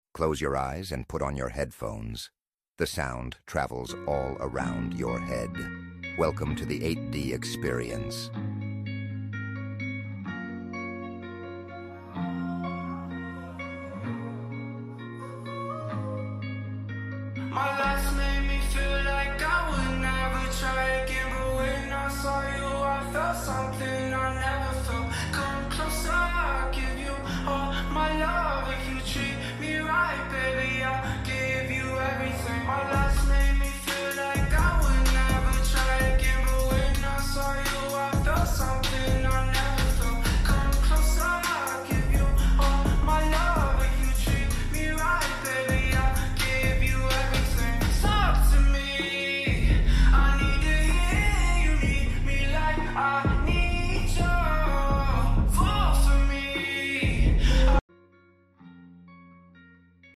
#8daudio #8DSound